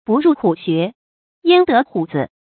注音：ㄅㄨˋ ㄖㄨˋ ㄏㄨˇ ㄒㄩㄝˊ ，ㄧㄢ ㄉㄜ ㄏㄨˇ ㄗㄧˇ
不入虎穴，焉得虎子的讀法